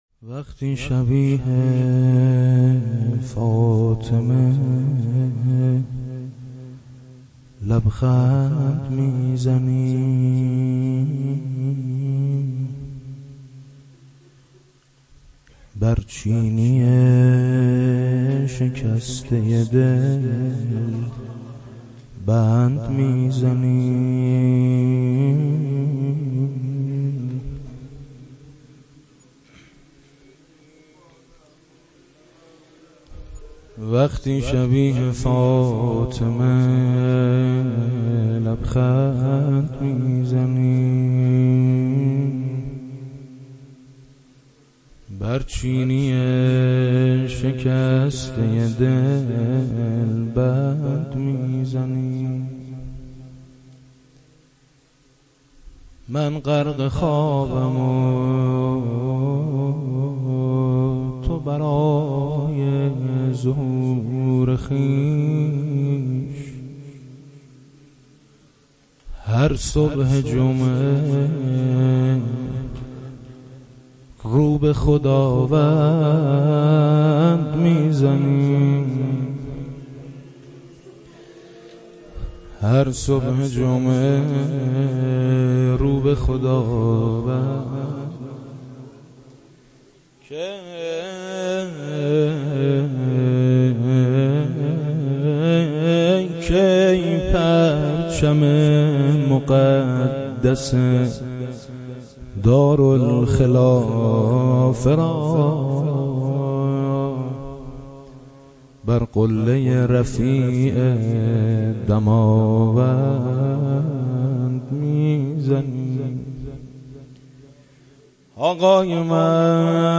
مناجات با امام زمان ع با صدای میثم مطیعی -( وقتی شبیه فاطمه لبخند می زنی )